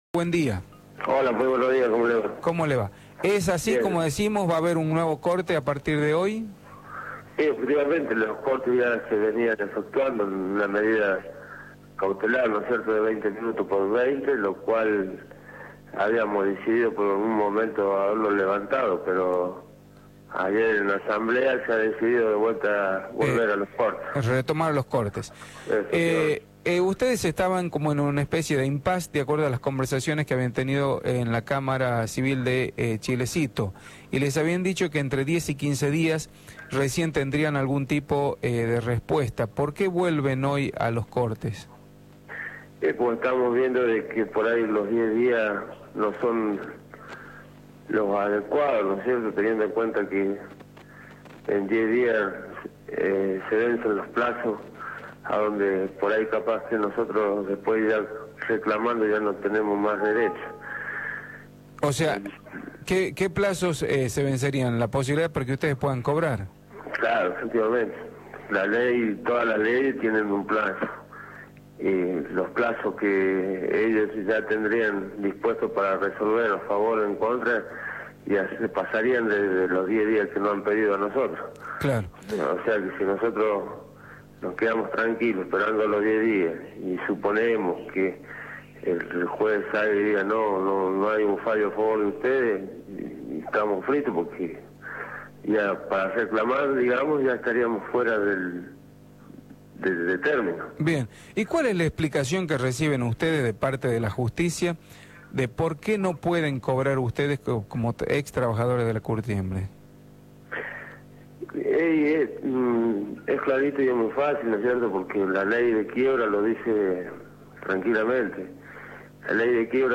por Radio La Red